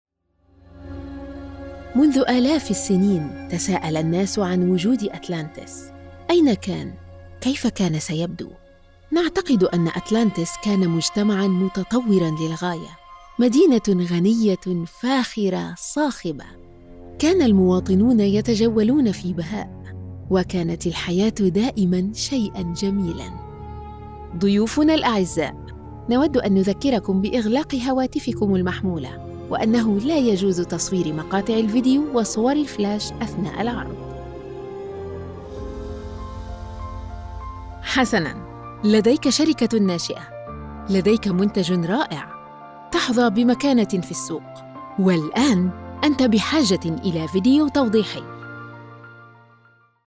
Female
Medical Narration-Explanatory
Modern Standard Arabic-Guide
Words that describe my voice are Warm, Conversational.
1106MSA-Atlantis-Tour_Guide.mp3